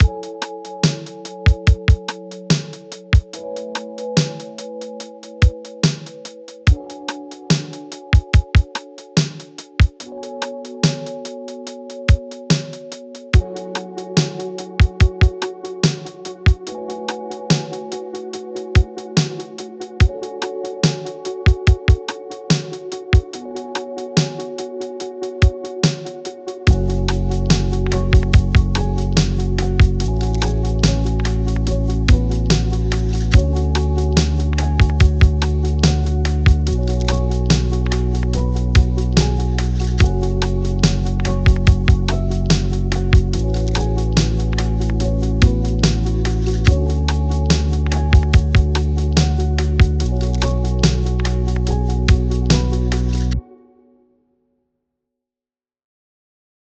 Lo-Fi Matte Brown Cut 60.wav